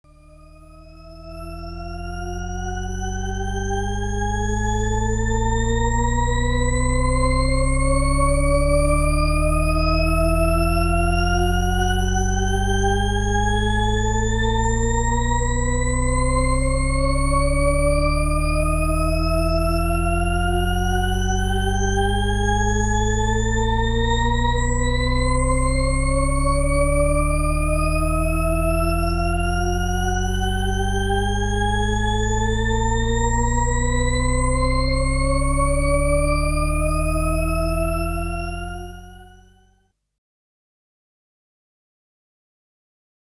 Rissetton_anst.AIFF